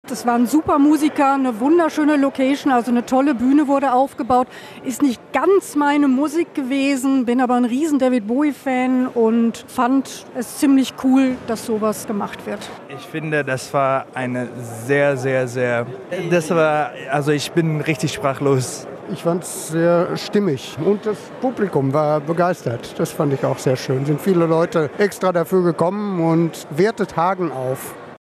Konzert im Hauptbahnhof
In der Eingangshalle des Hauptbahnhofs steht die Bühne.